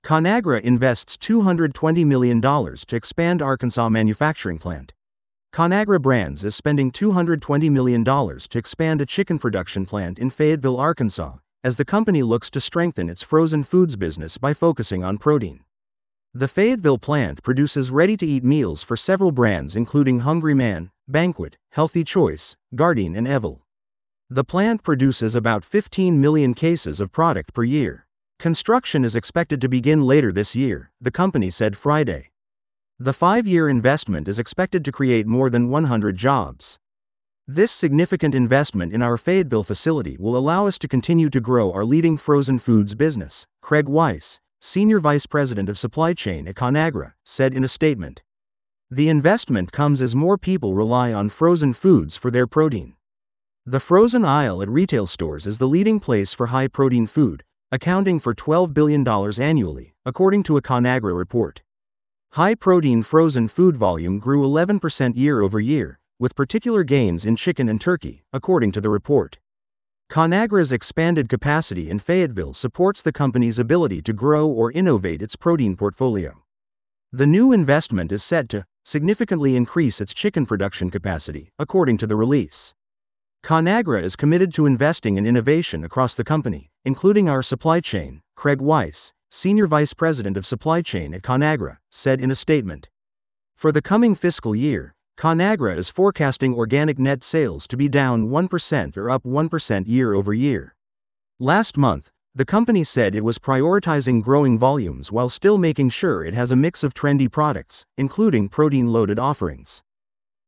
This audio is generated automatically.